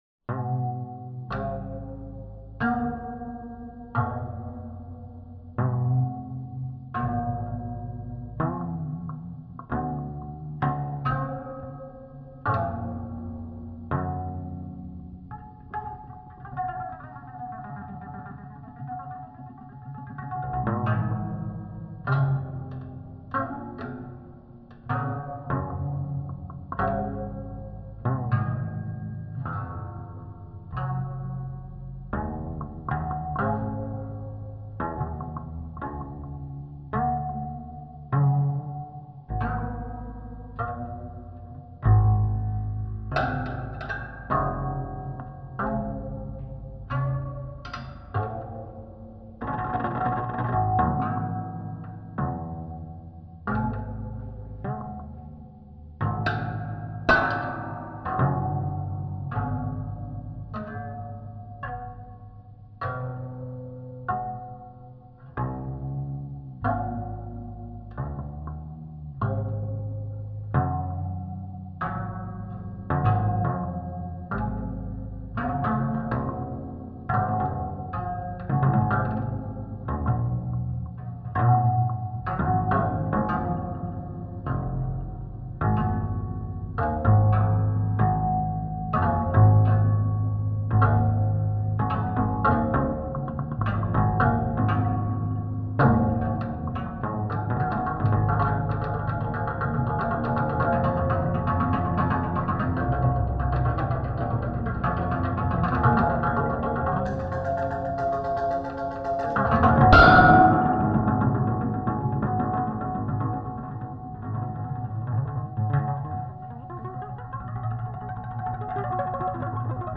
Textural solo improvisations with bow on the springboard.